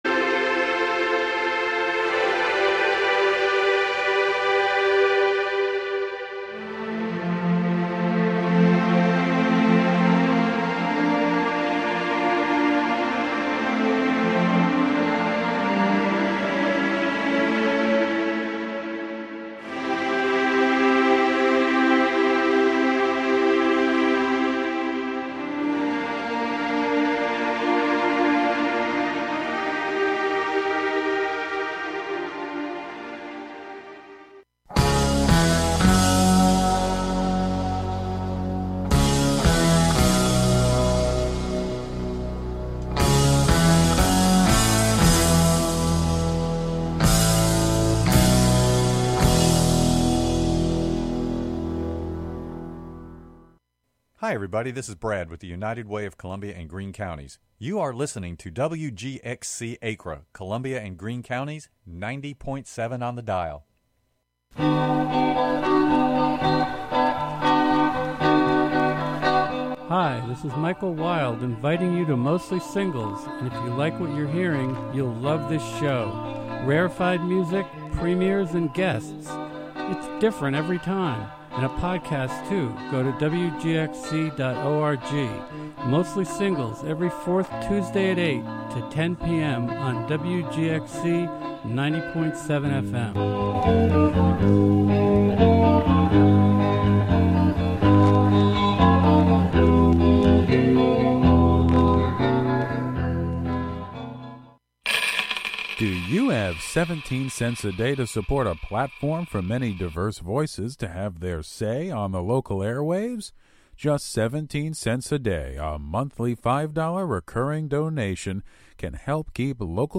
An upbeat music show featuring the American songbook, as interpreted by contemporary artists as well as the jazz greats in a diverse range of genres from Hudson, New York. This week a tribute to strong and resilient women.